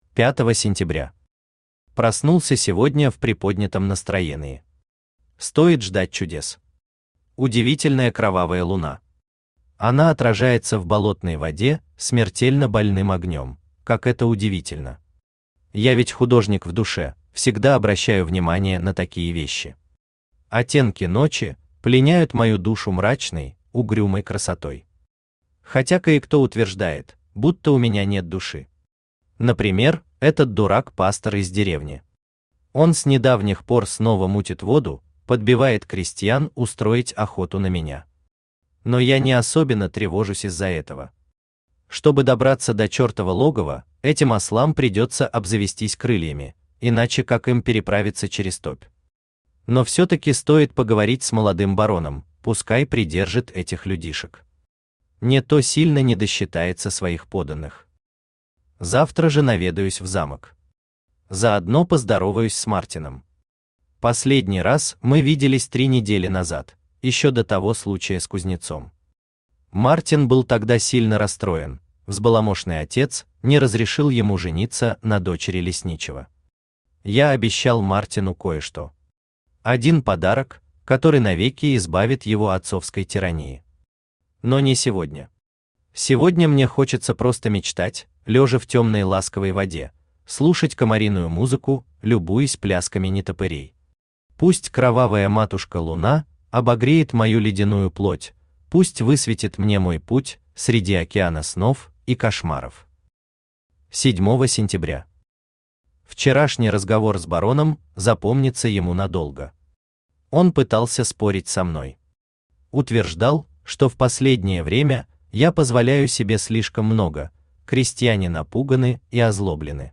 Аудиокнига Дневник Монстра | Библиотека аудиокниг
Aудиокнига Дневник Монстра Автор Владислав Георгиевич Тихонов Читает аудиокнигу Авточтец ЛитРес.